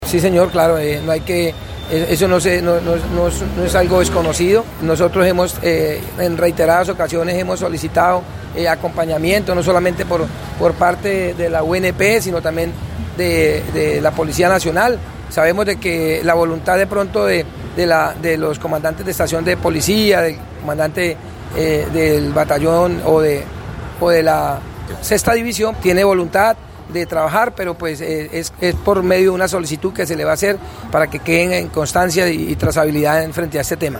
Wilman Fierro Lugo, diputado por Alianza Verde y segundo vicepresidente de la asamblea departamental, dijo que, son pocas las medidas que adelanta la Unidad Nacional de Protección, en procura de salvaguardar la integridad de quienes hacen parte de la corporación.